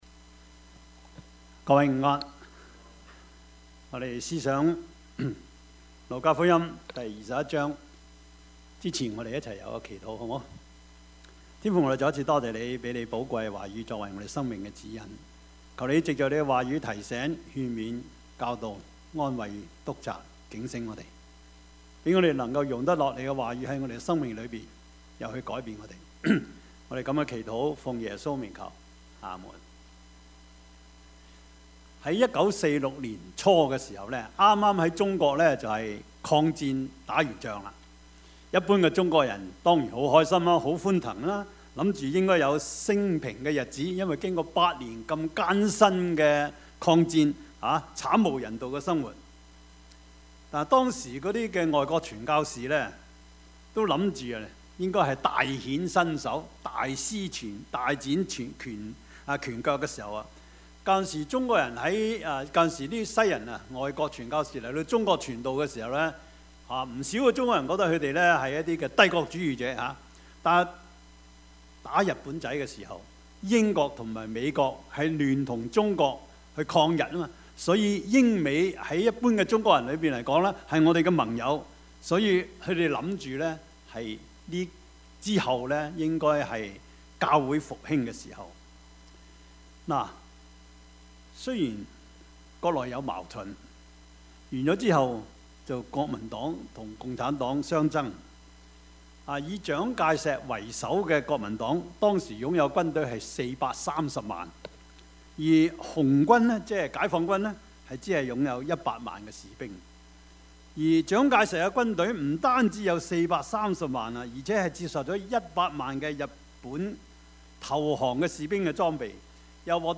Passage: 路加福音 21:5-24 Service Type: 主日崇拜
Topics: 主日證道 « 聽誰的?